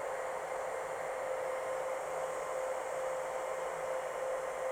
ambient_hiss.wav